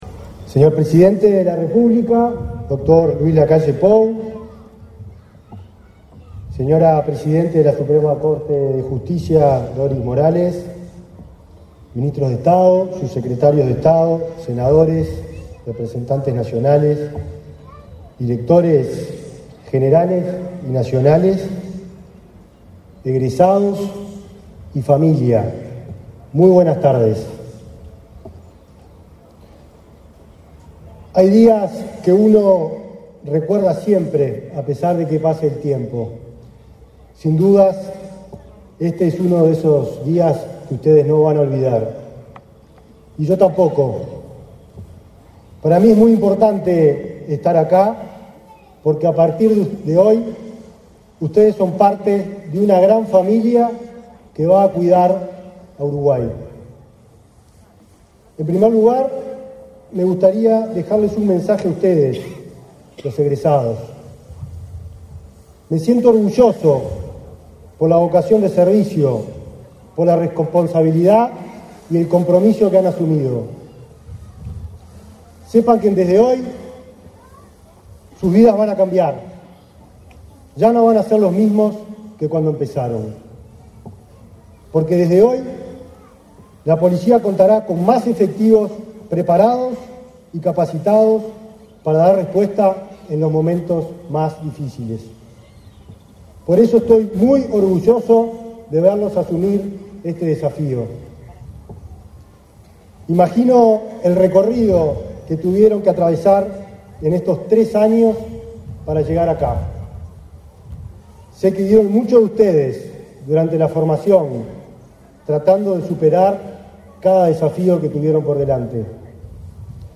Palabras del ministro del Interior, Nicolás Martinelli
Con la participación del presidente de la República, Luis Lacalle Pou, se realizó, este 13 de diciembre, la ceremonia de egreso de la promoción LXXIV,
En el evento, disertó el ministro del Interior, Nicolás Martinelli.